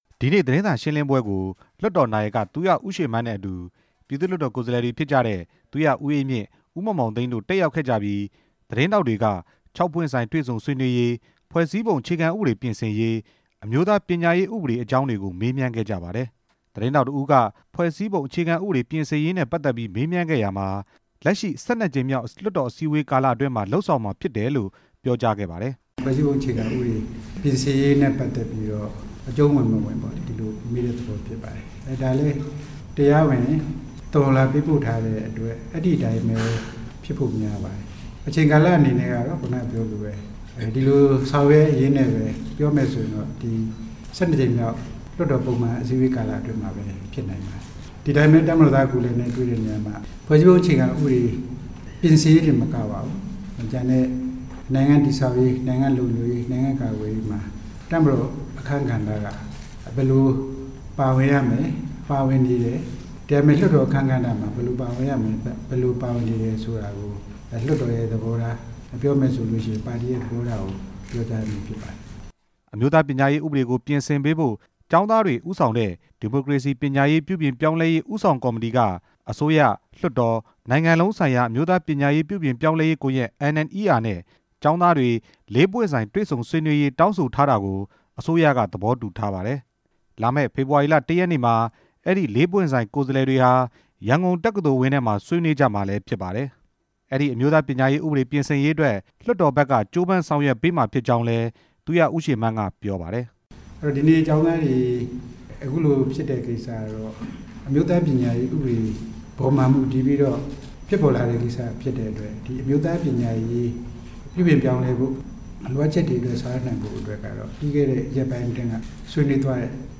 ပြည်ထောင်စုလွှတ်တော် နာယက သူရဦးရွှေမန်းဟာ ဒီနေ့ လွှတ်တော်ဝင်းအတွင်းမှာရှိတဲ့ ဇမ္ဗူသီရိခန်းမမှာ သတင်းစာရှင်းလင်းပွဲ ပြုလုပ်ခဲ့ပါတယ်။